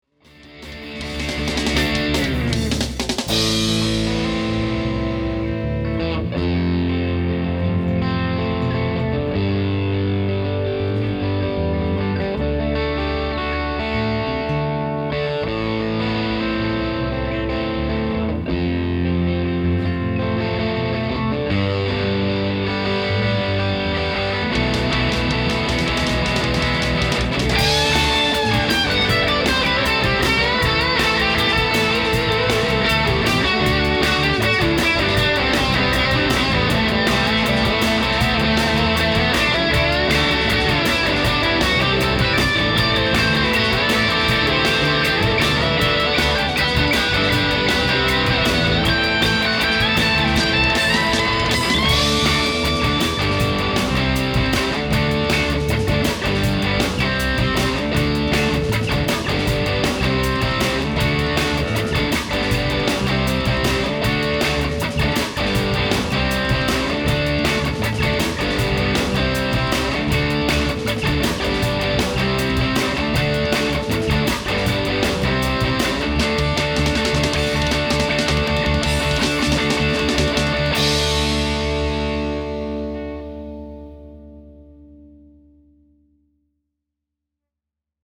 To demonstrate this magic, I’m going to share an excerpt from a song that I’m working on. I just finished laying down the instrument parts.
Talk about cranked Marshall-esque tones!
This setting gives me a sweet, singing lead voicing that sustains for days due to the awesome solid-state sag circuit! Again, the guitar was plugged straight into the amp – no frills whatsoever. I did add some reverb to both parts, but other than that, that the raw sound of the guitars/amps with no EQ. Pretty killer tones!!!
We’re talking conversation level, so if you heard some transient clicks in the clip, it’s stuff that was making noise in my garage/studio!